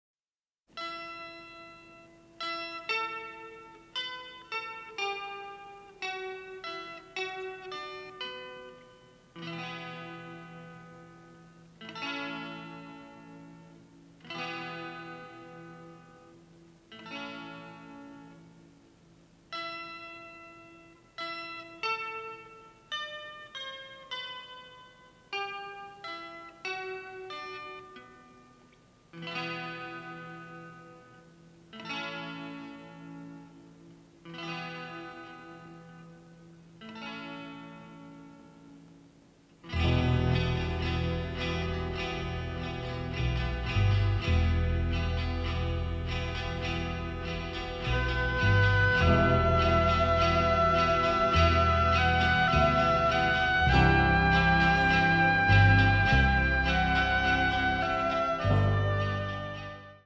western score